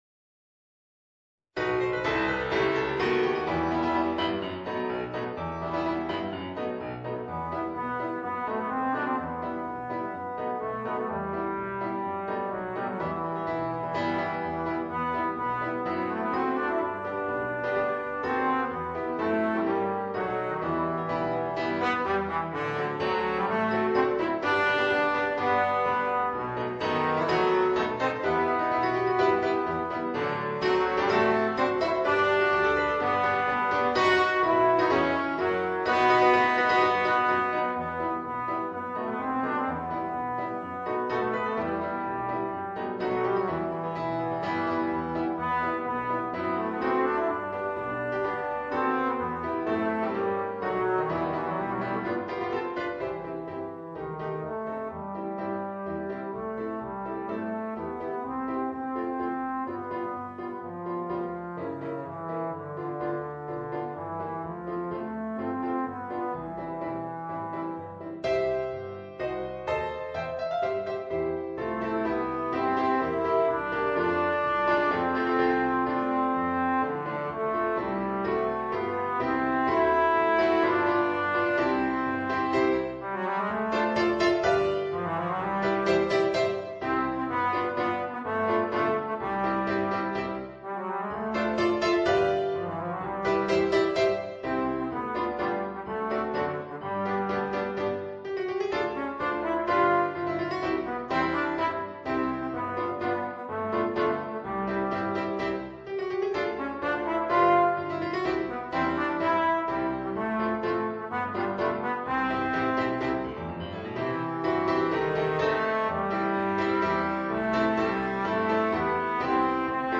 Trombone & Piano